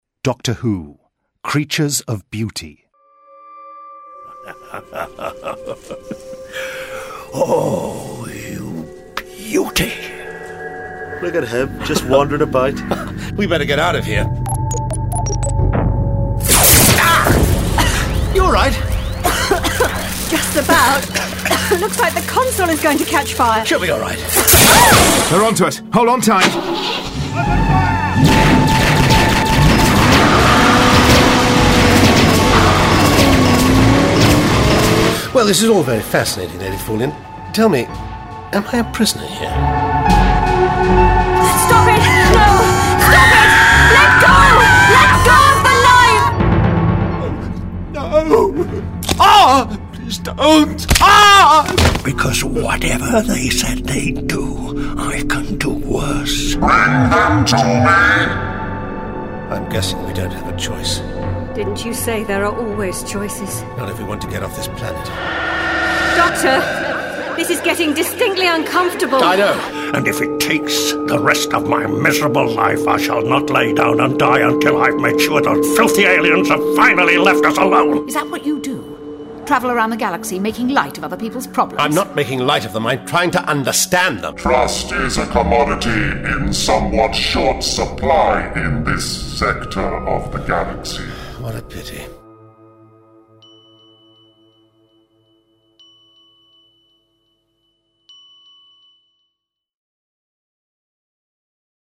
full-cast original audio dramas